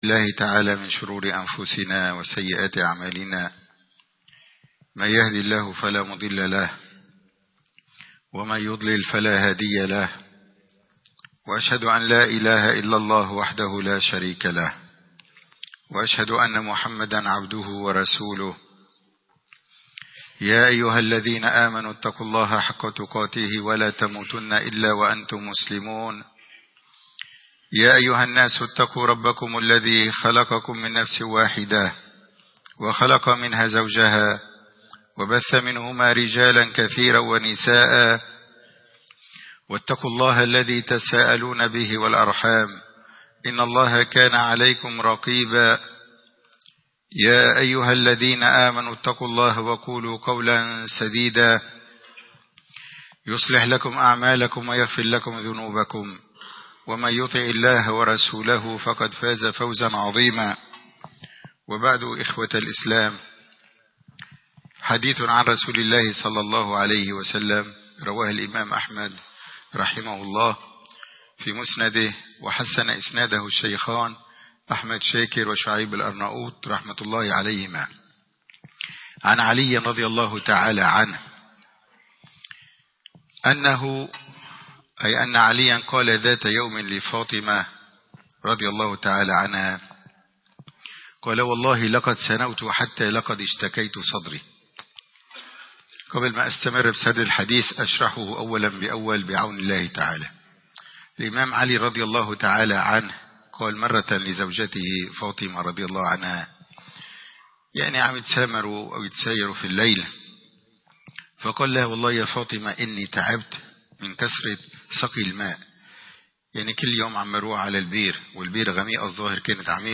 الخطب المنبرية
خطب الجمعة